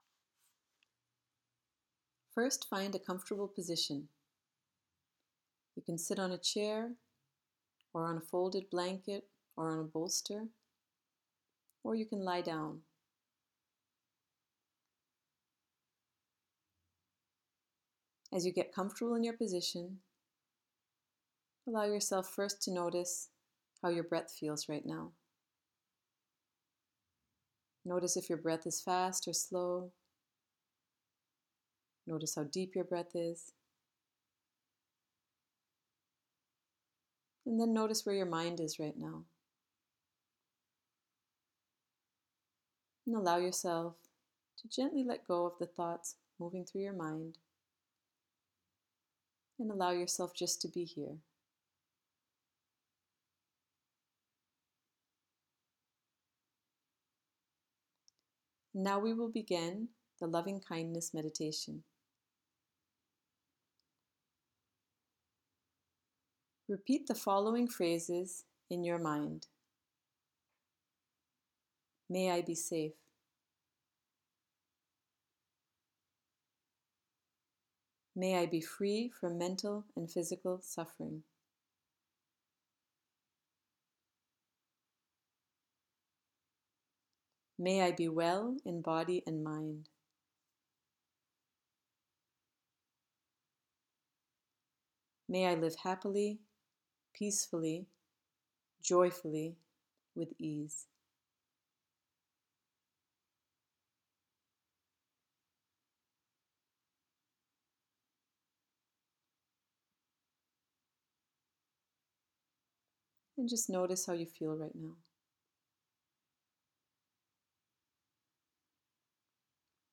Loving Kindness Meditation:
loving-kindness.m4a